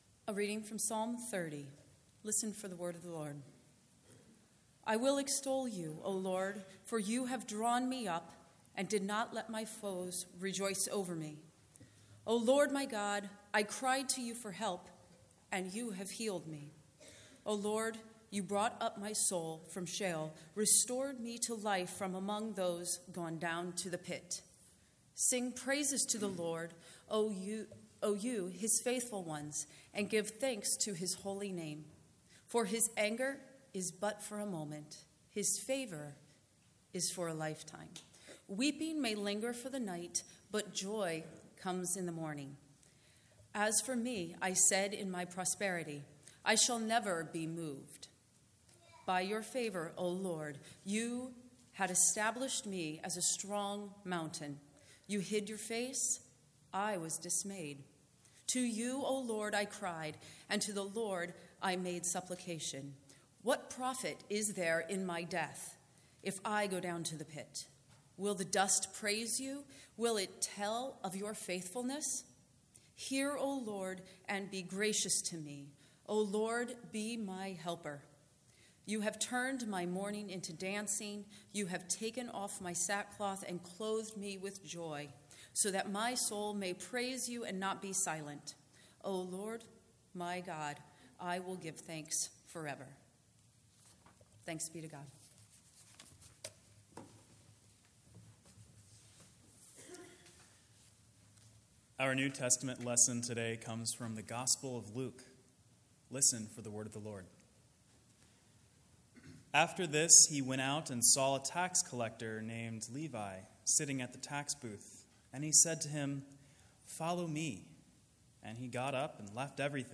Sermon March 11, 2018